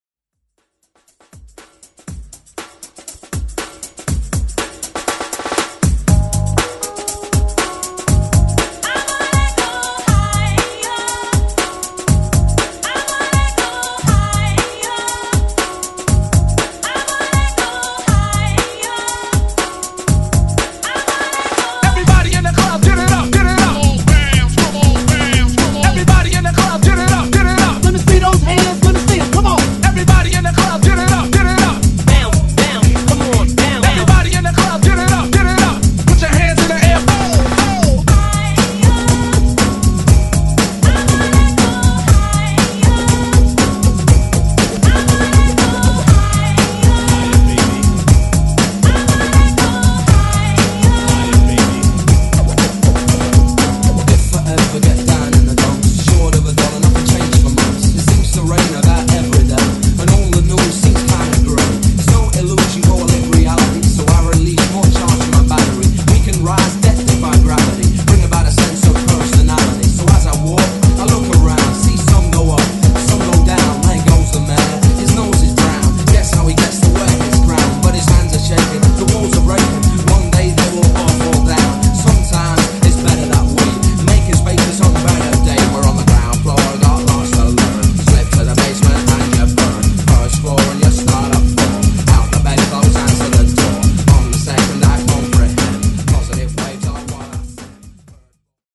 OLD SCHOOL HIPHOP , RE-DRUM